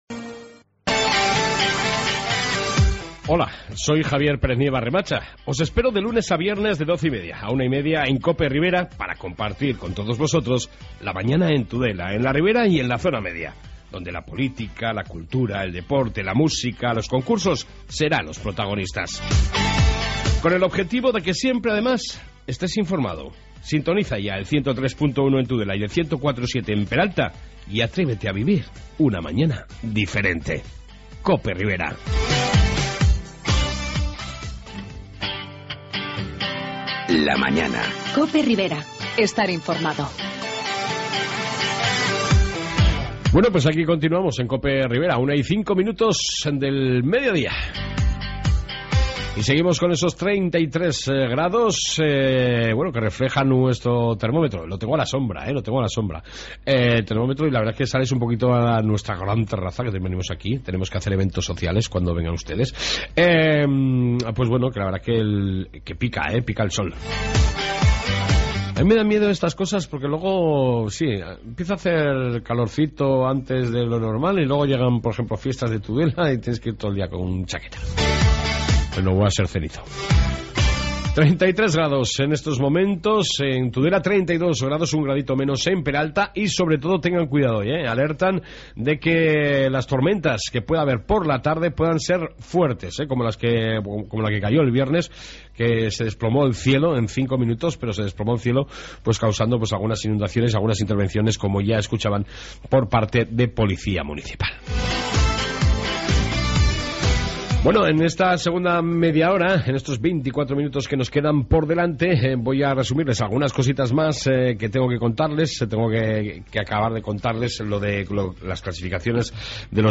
AUDIO: En esta 2 parte seguimos con la Información y entrevista sobre consumo y telefonia...